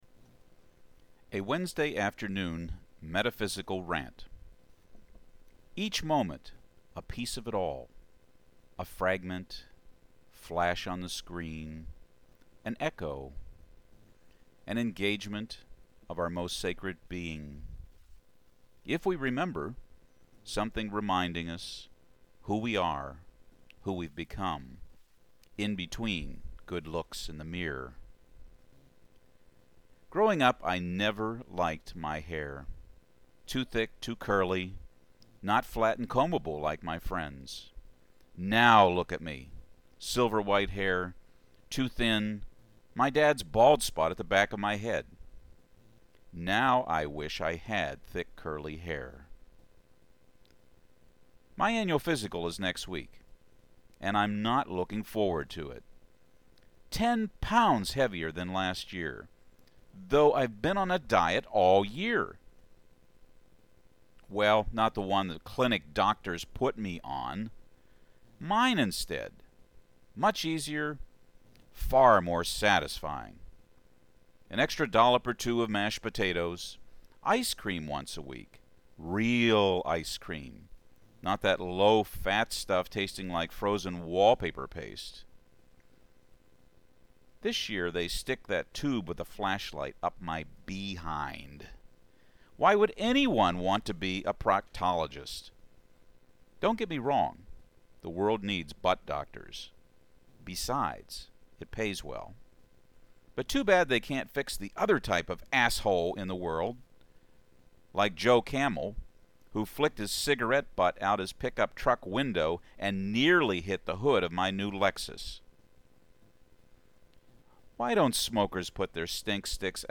Here is a poem I performed back in September. I have posted the poem with the prompts (found in caps and parentheses) I used in its reading so you can see how I approached the poem on stage.